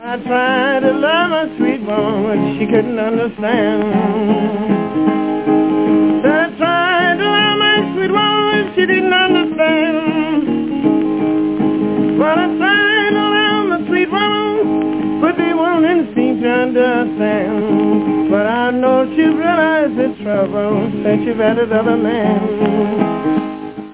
блюзовый гитарист и певец